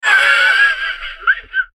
Wheezing Laugh Sound Effect
Description: Wheezing laugh sound effect. Male wheezy laugh. Twisted male laugh, perfect for TikTok, YouTube videos, games, apps, and more. Human sounds.
Wheezing-laugh-sound-effect.mp3